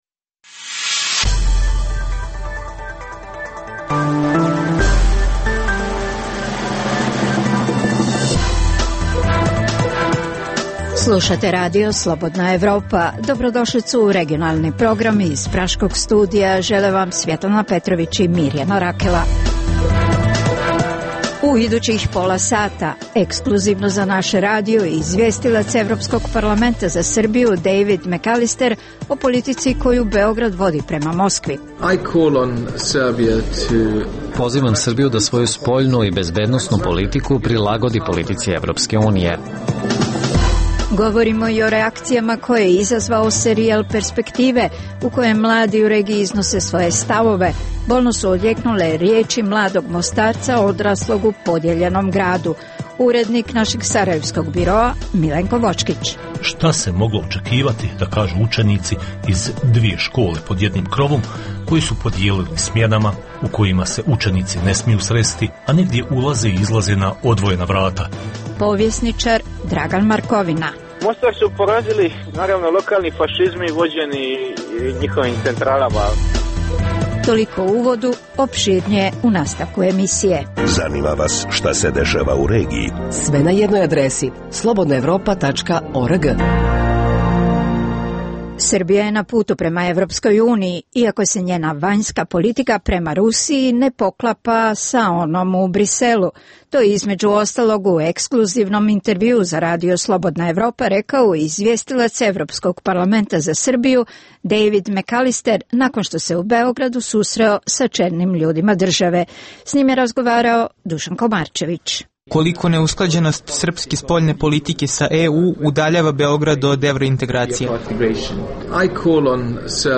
- Intervju sa izvjestiocem Evropskog parlamenta za Srbiju Dejvidom Mekalisterom.